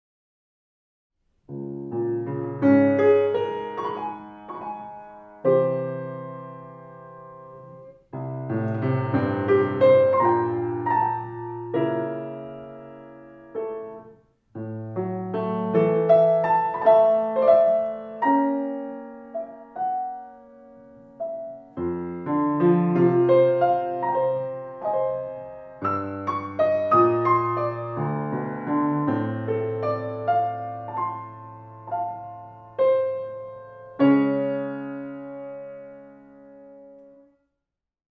plaudernd